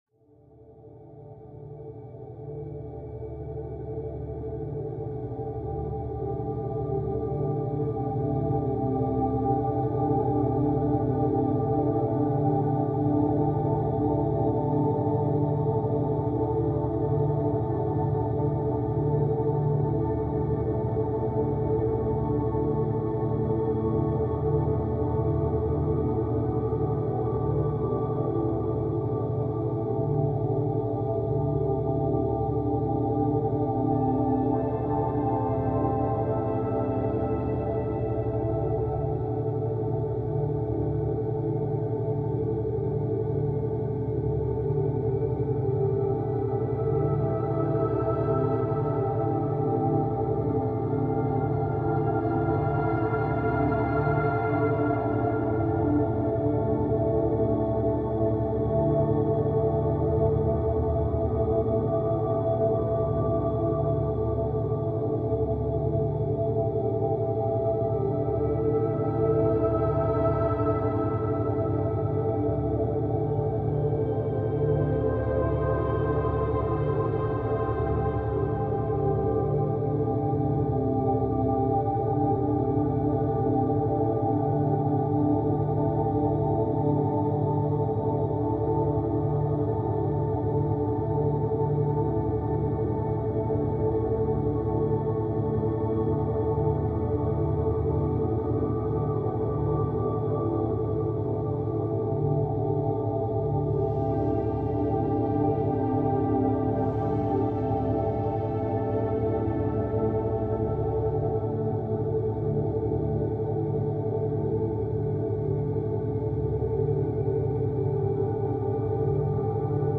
Pyramid Meditation – 33 Hz + 9 Hz Frequencies for Ancient Healing